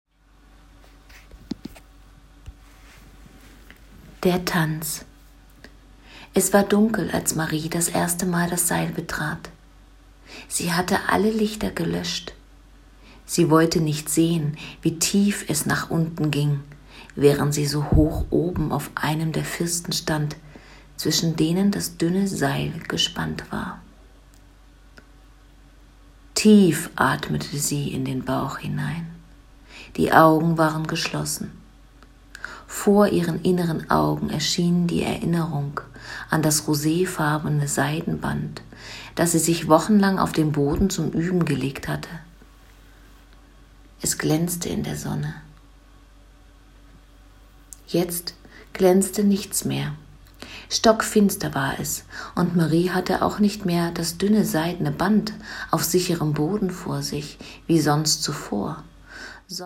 Lyrische Kurzgeschichte: „Die Seiltänzerin”